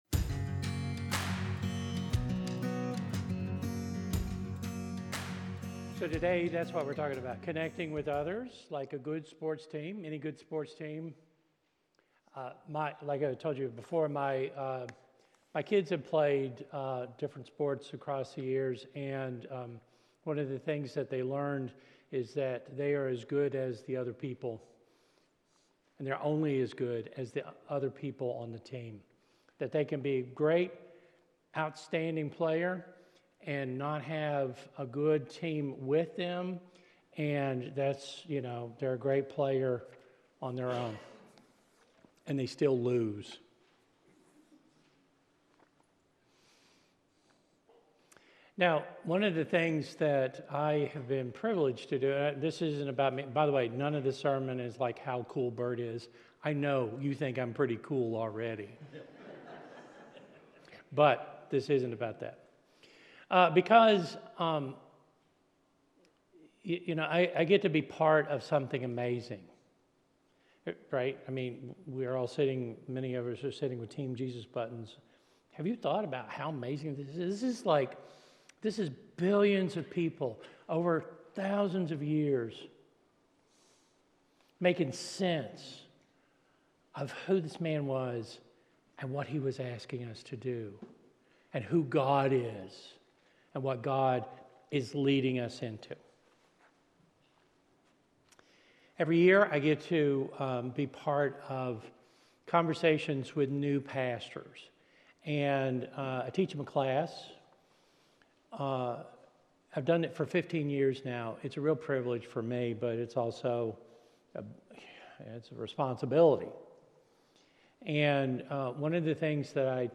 Through personal anecdotes and Biblical wisdom, he emphasizes that despite our differences, we are all created in God's image and called to love one another. The sermon encourages listeners to break down barriers and support each other in both big and small ways.